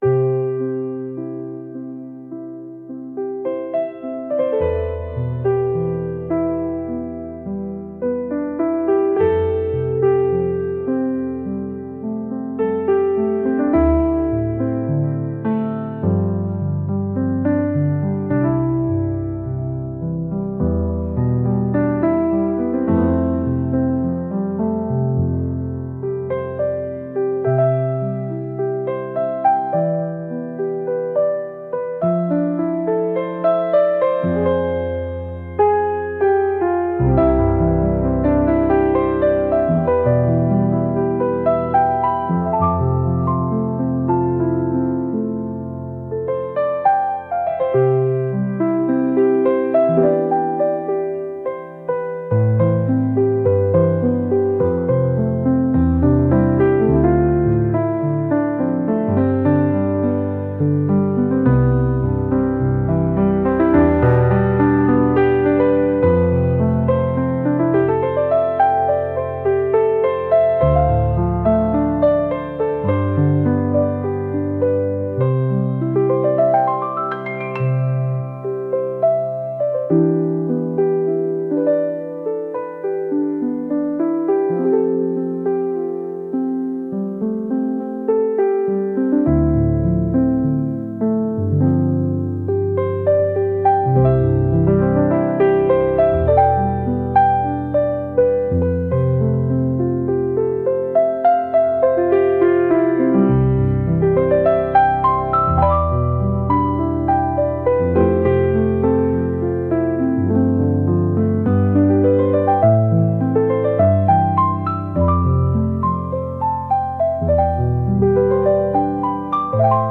星の間をゆっくり飛行するようなピアノ曲です。